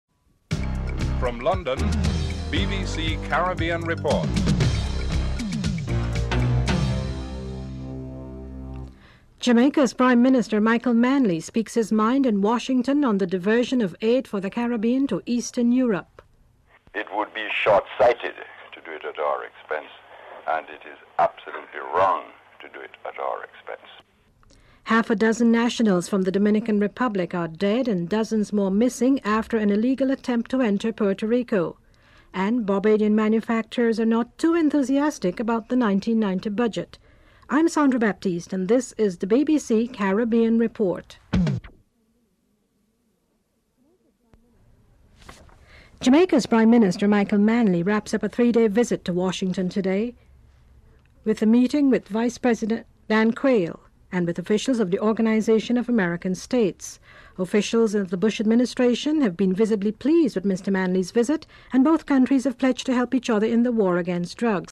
1. Headlines (00:00-00:44)
2. Jamaica's Prime Minister, Michael Manley, speaks in Washington on the diversion of US aid for the Caribbean to Eastern Europe.